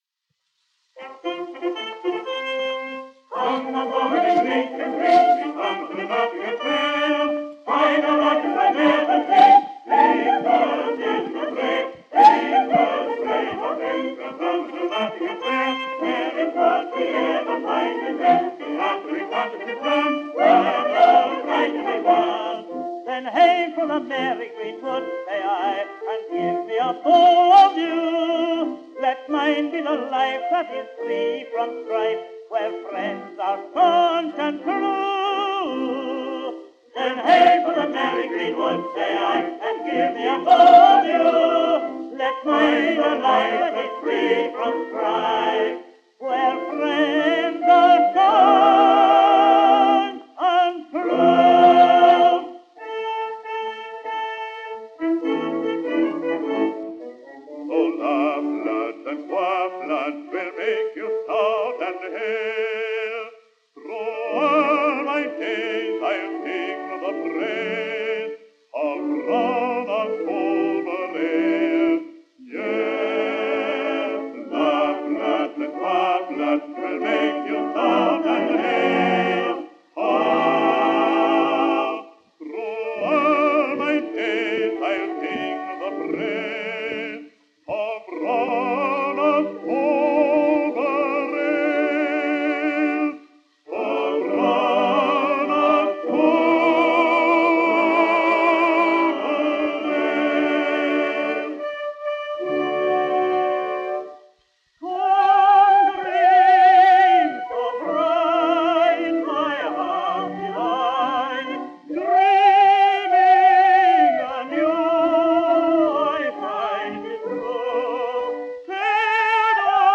Historische Aufnahmen (MP3)